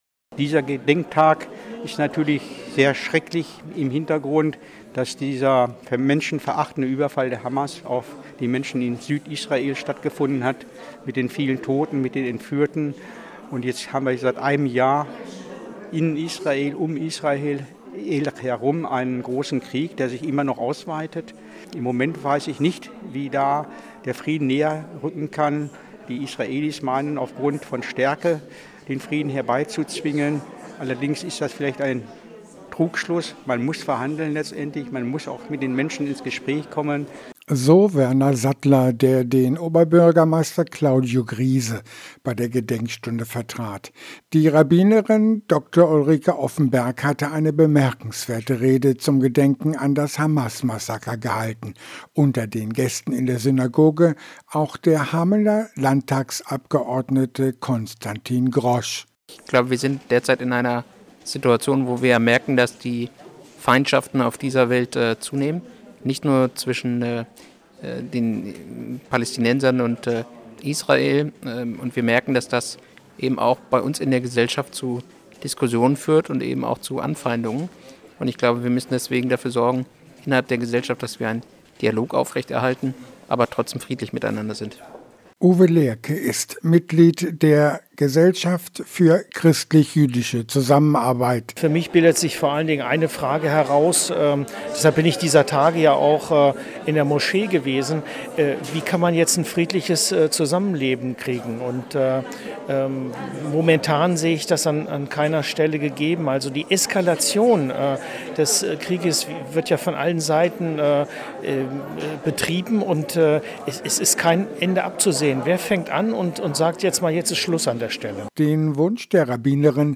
Hameln: Besucherstimmen zur Gedenkstunde in der jüdischen Gemeinde – radio aktiv
hameln-besucherstimmen-zur-gedenkstunde-in-der-juedischen-gemeinde.mp3